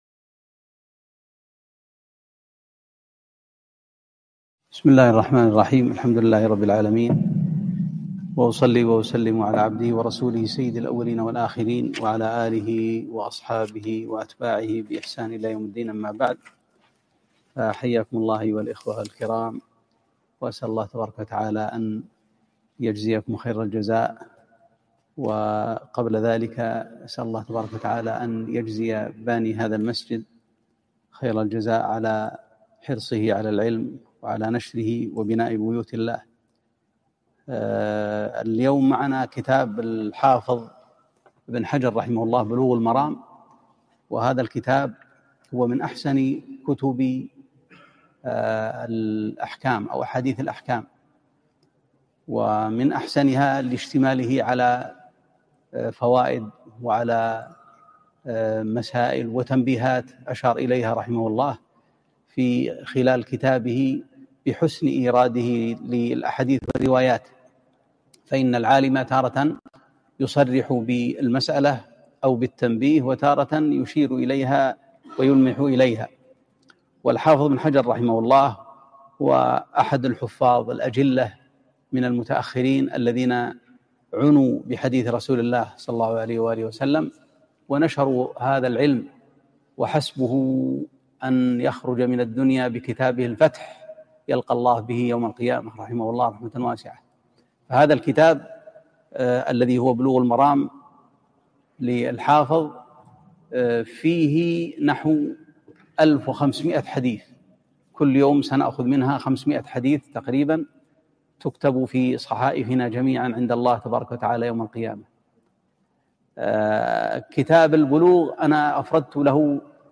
(١) مجلس سماع وتعليق بلوغ المرام لابن حجر العسقلاني